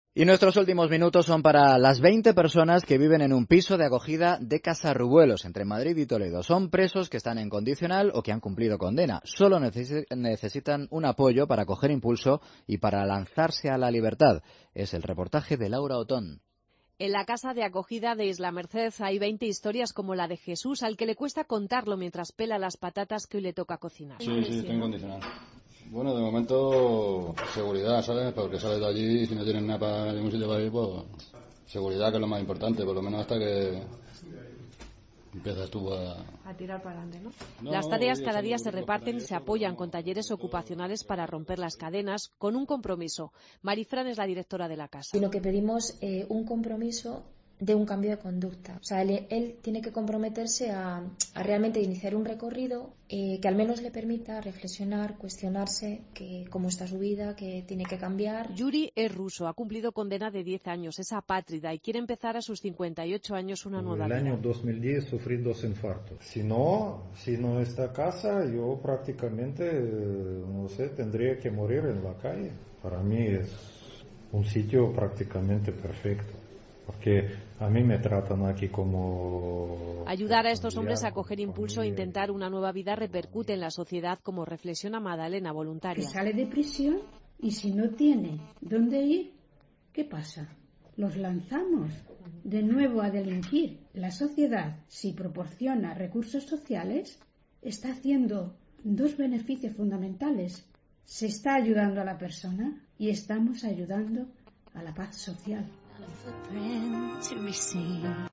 La vida tras la cárcel. Reportaje